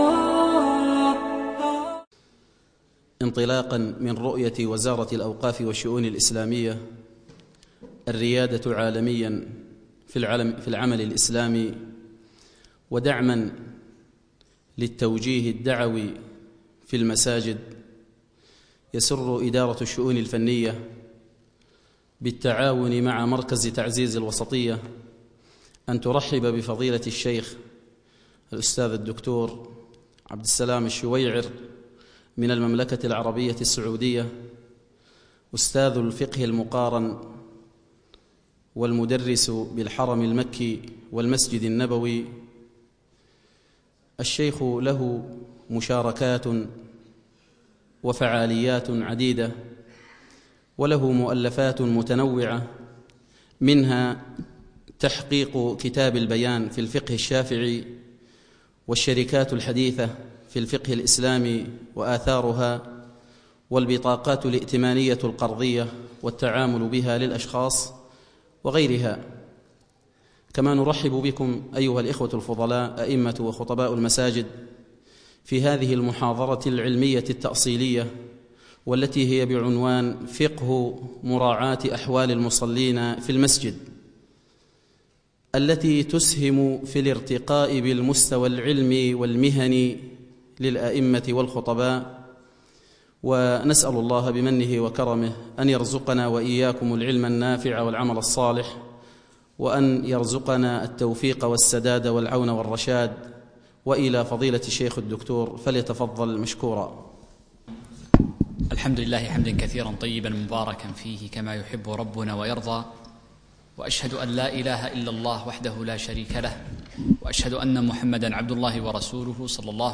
محاضرة - فقه مراعاة المصلين في المساجد